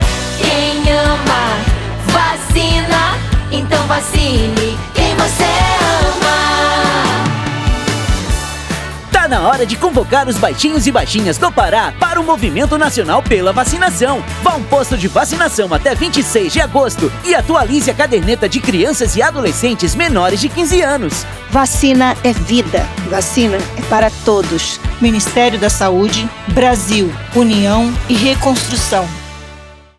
Áudio - Spot 30seg - Campanha de Multivacinação no Pará - 1,1mb .mp3